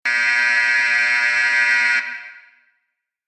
Basketball Buzzer 3 Sound Effect Download | Gfx Sounds
Basketball-buzzer-3.mp3